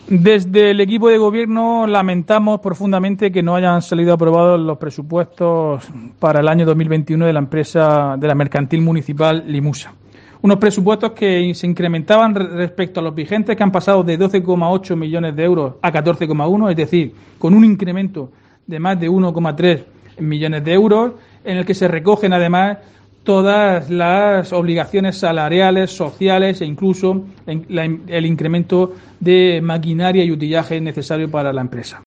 Diego José Mateos, alcalde de Lorca sobre LIMUSA